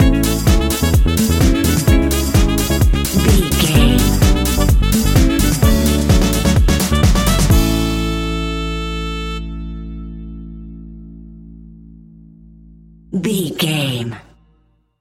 Ionian/Major
groovy
uplifting
bouncy
cheerful/happy
electric guitar
horns
drums
bass guitar
saxophone
disco
synth
upbeat
instrumentals
clavinet